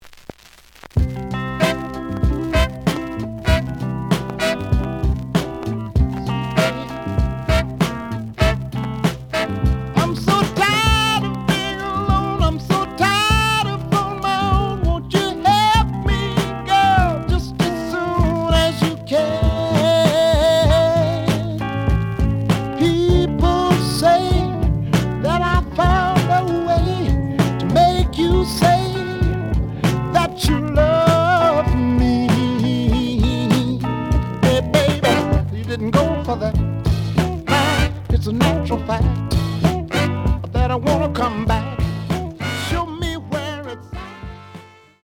The audio sample is recorded from the actual item.
●Genre: Soul, 70's Soul
Looks good, but slight noise on both sides.)